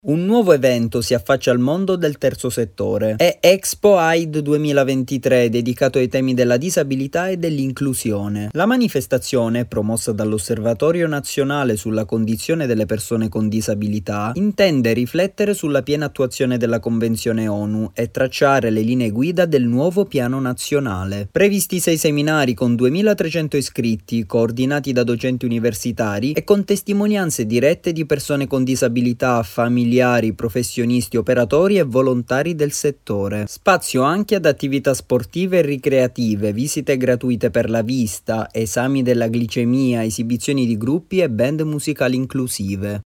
Oggi e domani al Palacongressi di Rimini la prima edizione di Expo Aid. Il servizio